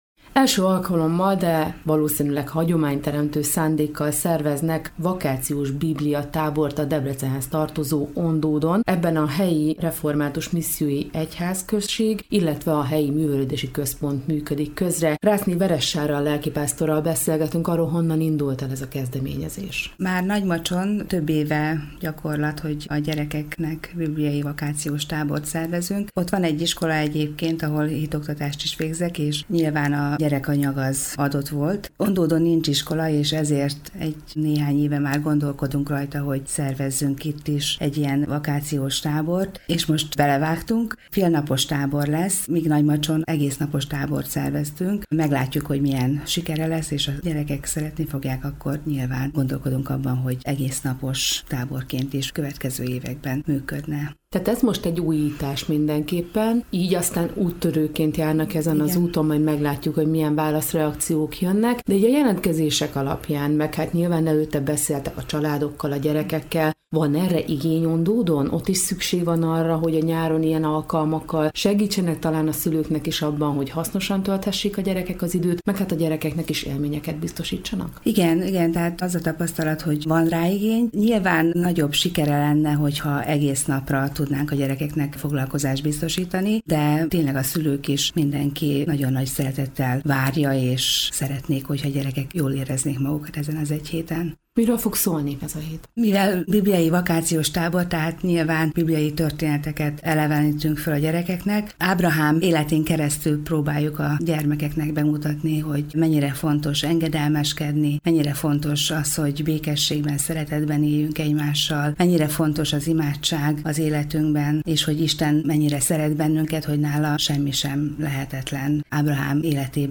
Letöltés Forrás: Európa Rádió